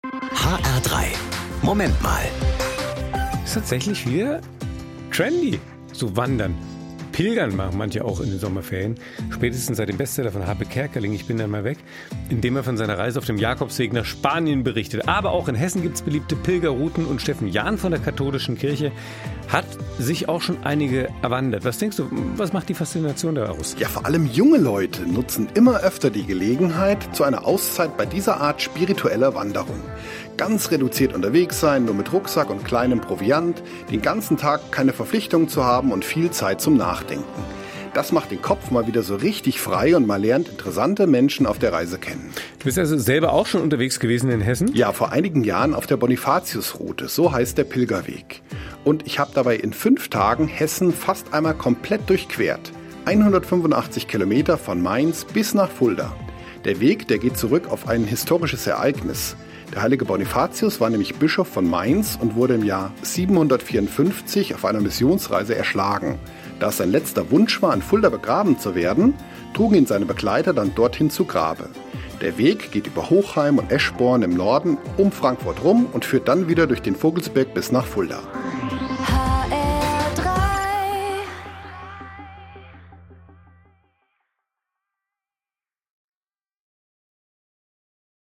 Eine Sendung von